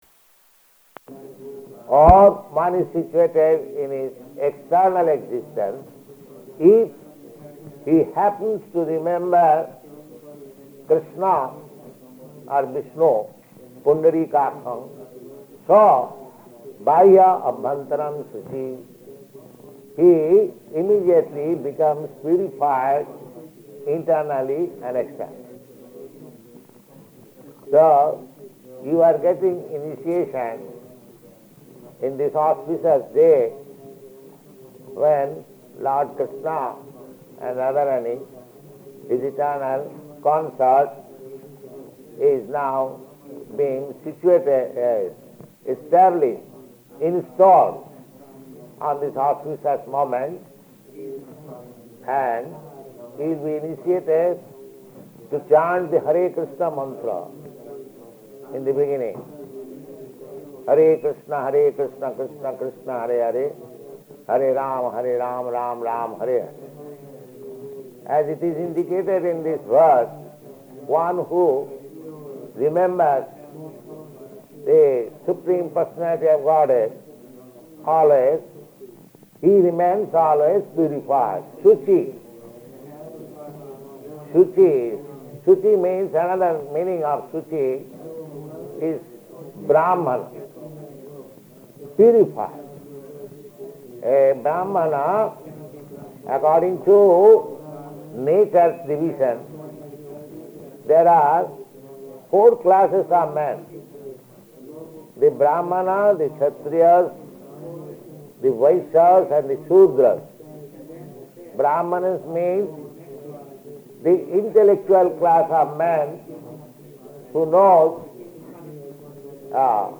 Deity Installation, Wedding and Initiations
Type: Initiation